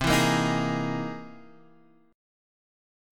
Caug7#9 chord {x 3 2 3 4 4} chord